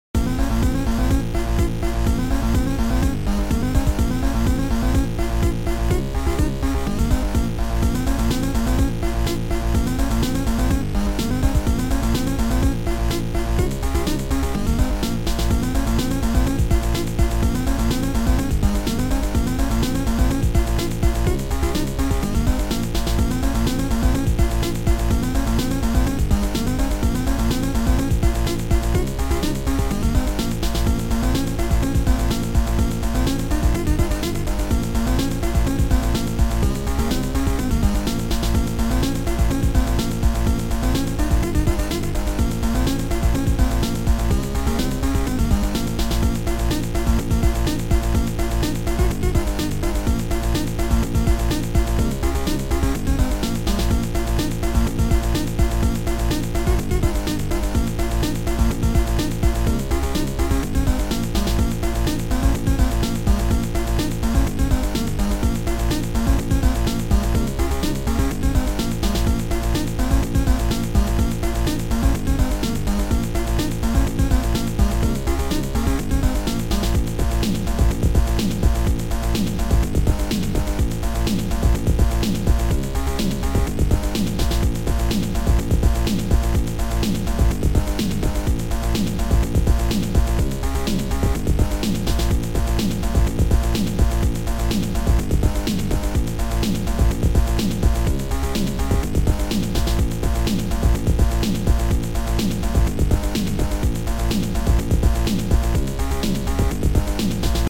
Soundtracker 15 Samples